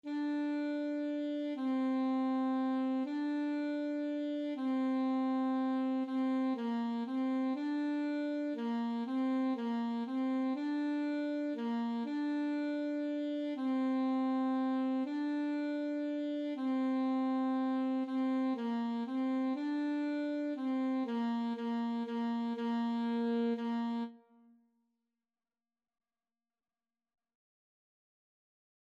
Free Sheet music for Alto Saxophone
3/4 (View more 3/4 Music)
Bb4-D5
Classical (View more Classical Saxophone Music)